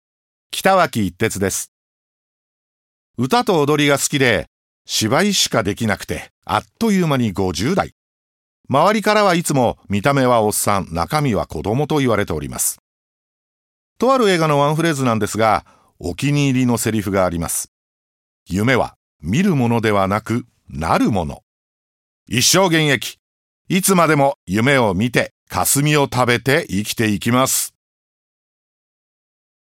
● 自己紹介